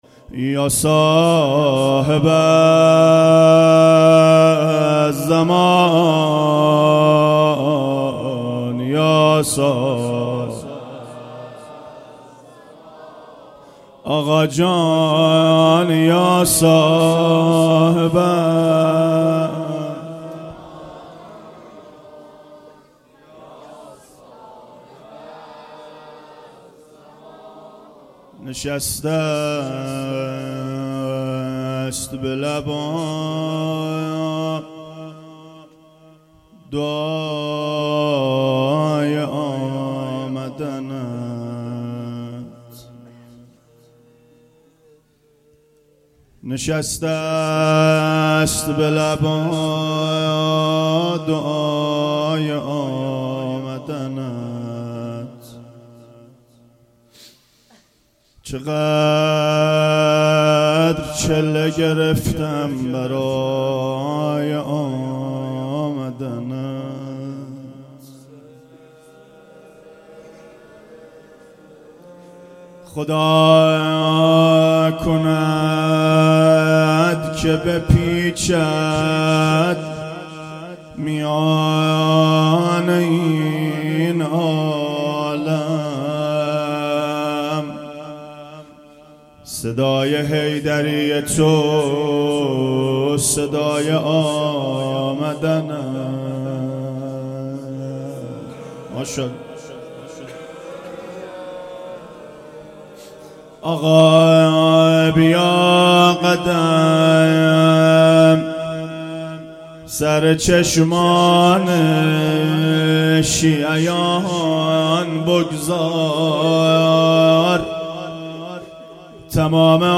روضه شب پنجم فاطمیه